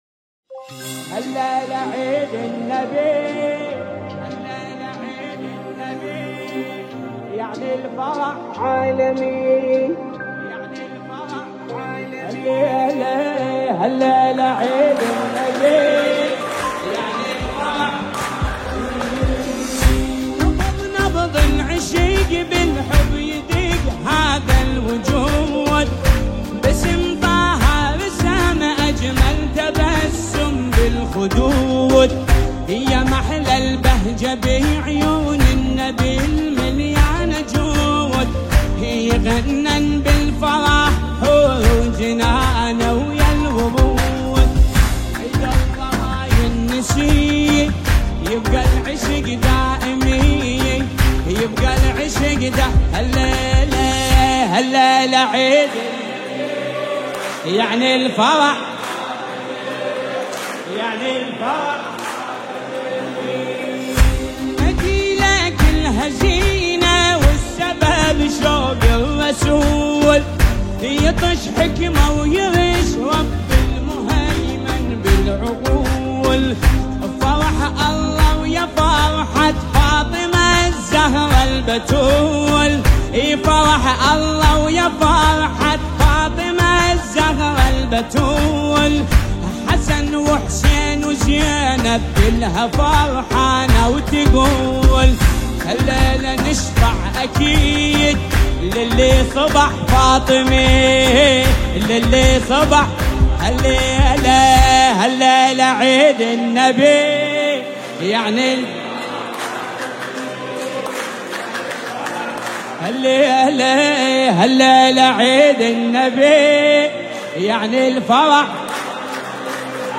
نماهنگ عربی زیبای